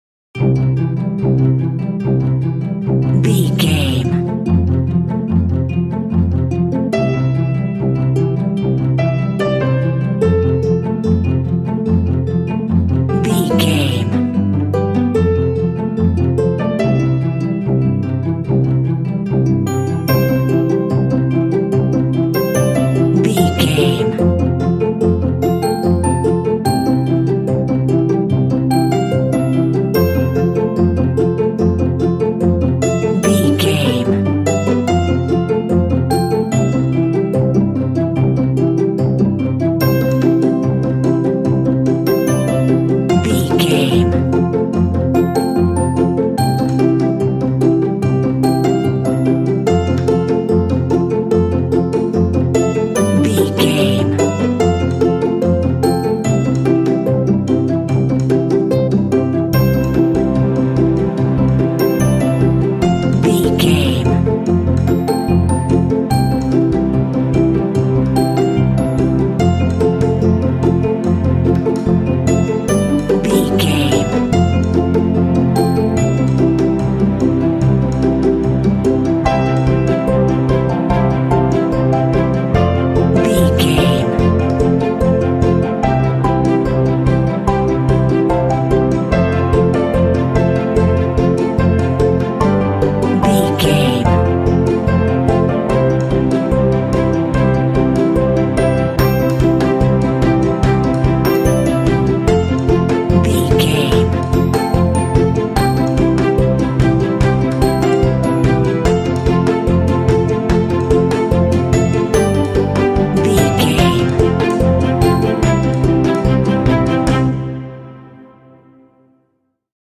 Ionian/Major
Fast
energetic
strings
piano
harp
percussion
contemporary underscore